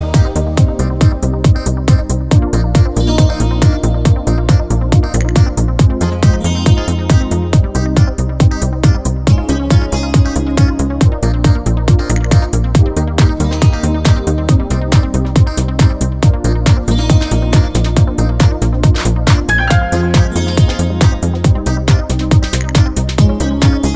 Dance Mix Dance 3:54 Buy £1.50